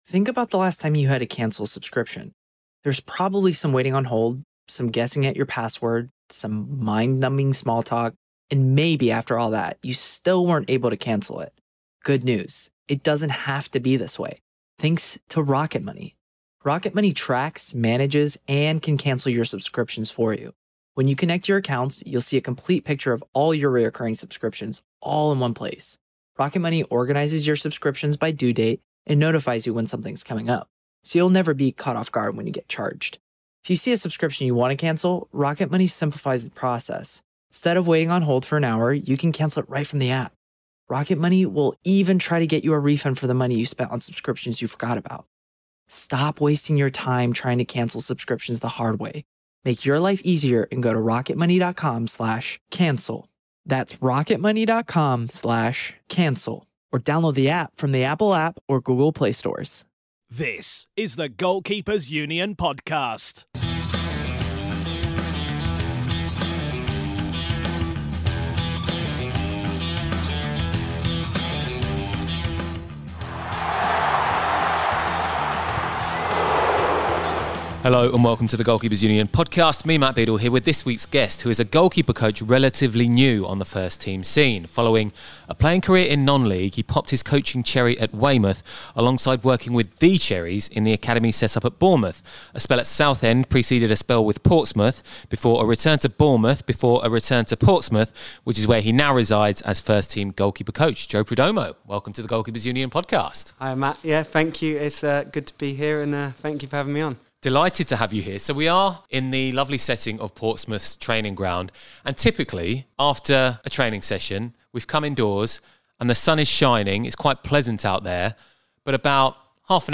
This week's feature interview is another enlightening discussion with a goalkeeper coach.